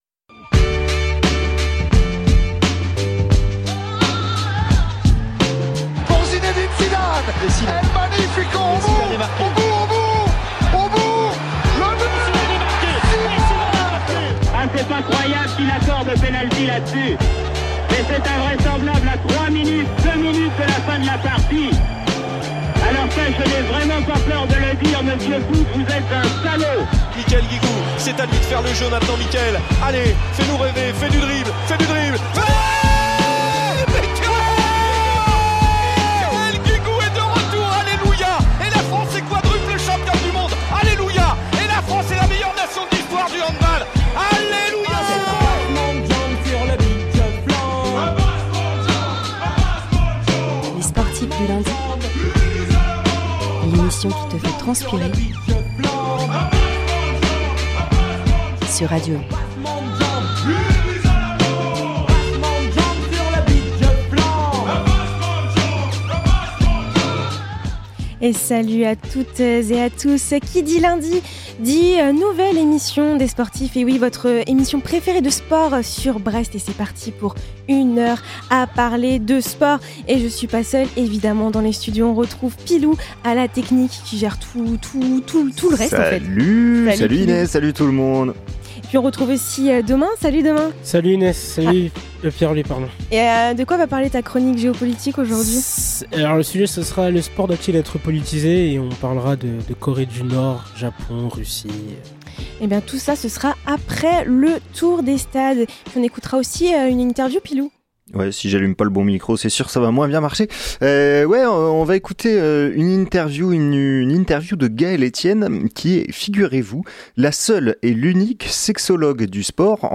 Chaque lundi de 19 à 20h, une bande de joyeuses et joyeux lurons prennent l'antenne pour causer de sport. Débats, résumés de matches, chroniques, quizz et interviews axées sur le sport local : voilà…